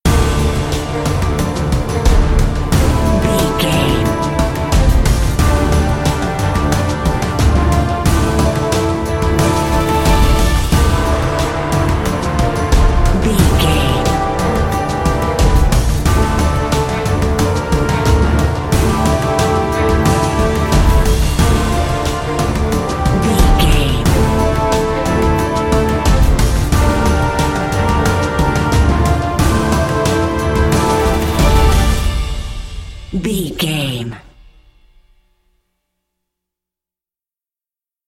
Epic / Action
Aeolian/Minor
strings
drums
cello
violin
synthesiser
orchestral hybrid
dubstep
aggressive
energetic
intense
synth effects
wobbles
driving drum beat